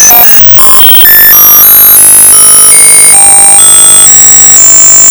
768D INC C Increase the tone frequency by one (higher pitch).
out-of-fuel.wav